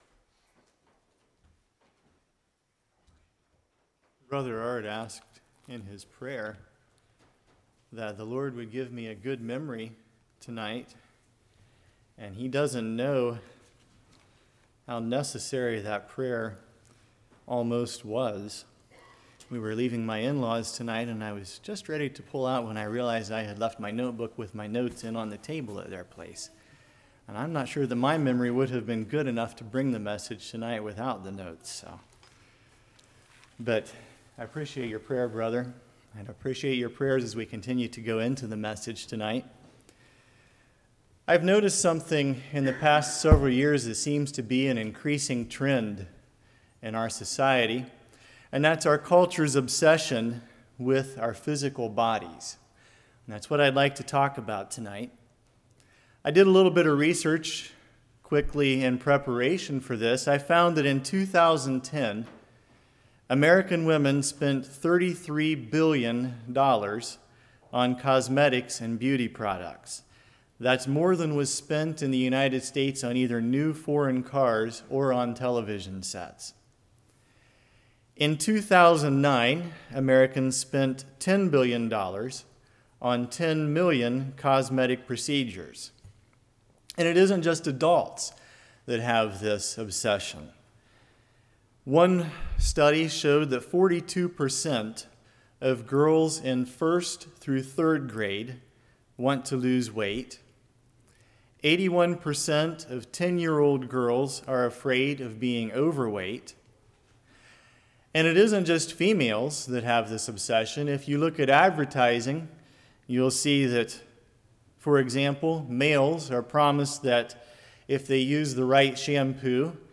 Genesis 1:26-2:7 Service Type: Evening Man’s View Biblical View God’s Claim « Lessons Learned from the Work Place If Jesus Can